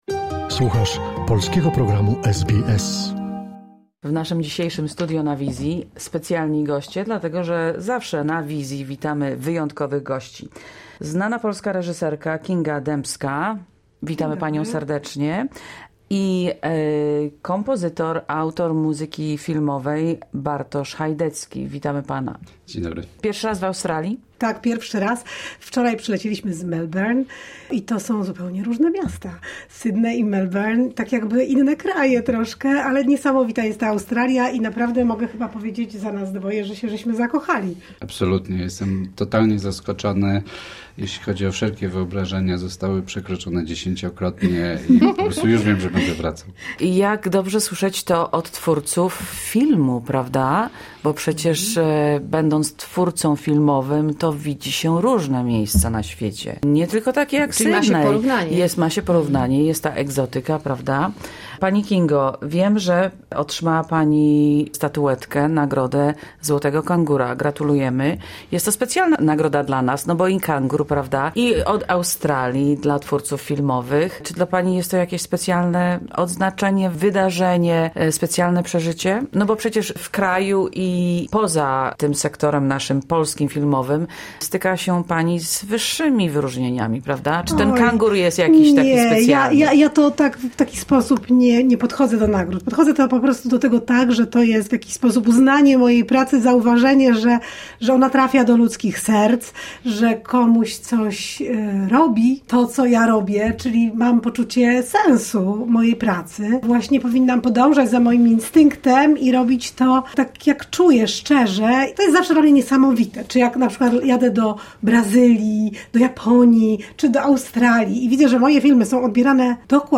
Kinga Dębska i Bartosz Chajdecki w studio SBS